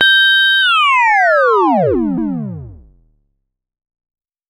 Index of /musicradar/essential-drumkit-samples/Vermona DRM1 Kit
Vermona Fx 05.wav